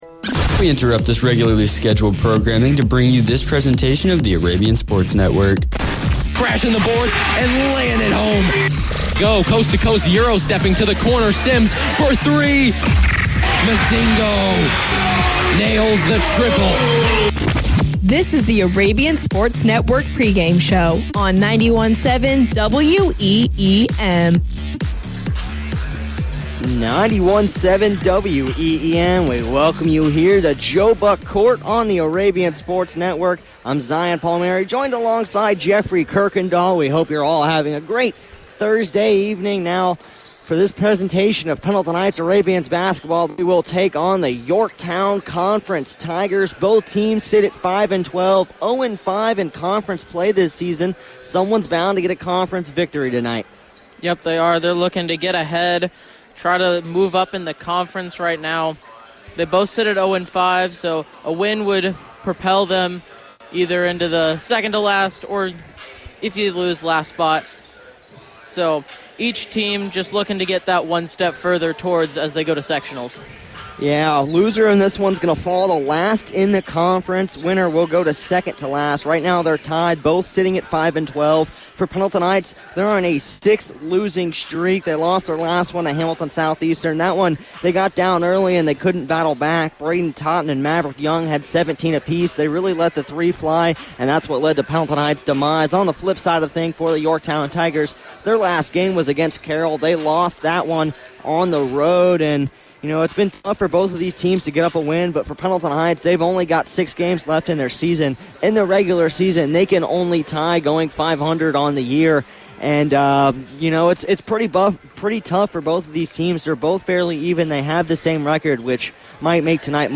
Varsity Boys Basketball Broadcast Replay Pendleton Heights vs. Yorktown 2-6-25